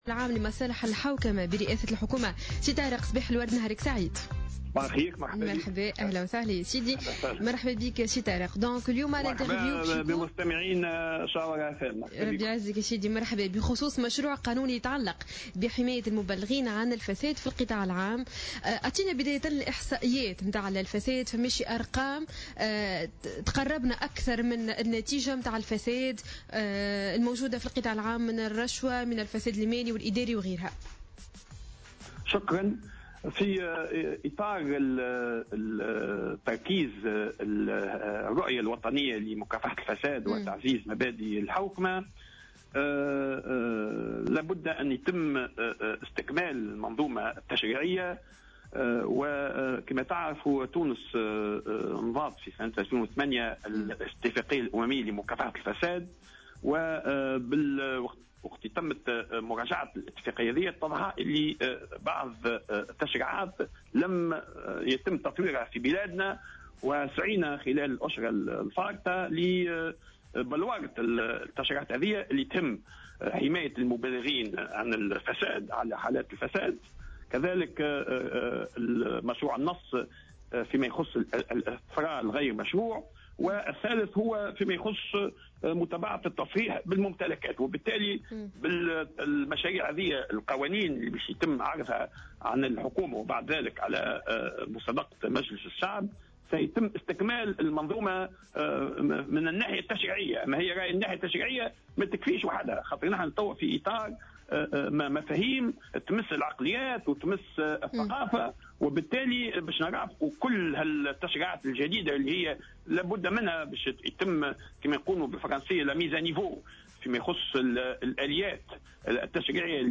أكد المدير العام لمصالح الحوكمة برئاسة الحكومة طارق البحري في مداخلة له على جوهرة "اف ام" صباح اليوم الجمعة 13 مارس 2015 أن لجنة تضم ممثلين عن وزارات وجهات معنية بتونس أعدت سلسلة من مشاريع القوانين التي تعني بتعزيز النزاهة ومكافحة الفساد من بينها مشروع قانون يتعلق بحماية المبلغين عن الفساد في القطاع العام .